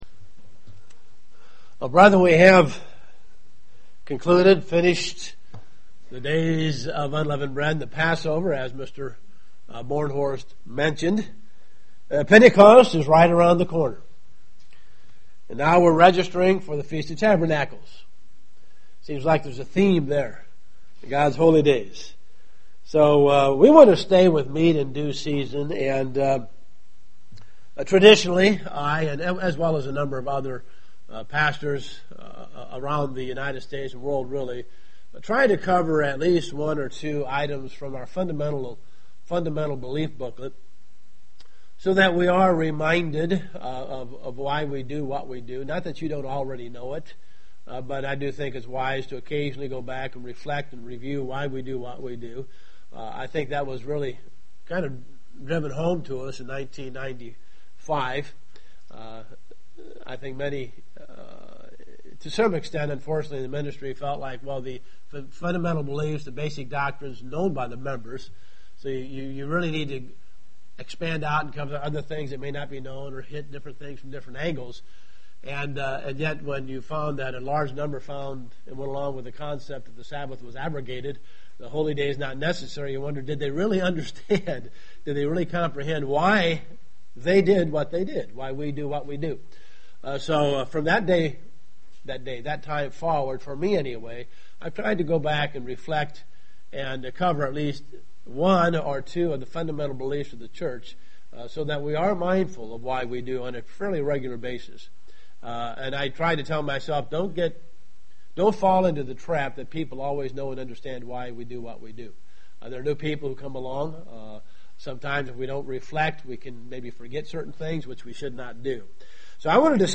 Given in Dayton, OH
Print God gave the festivals to all of us to keep UCG Sermon Studying the bible?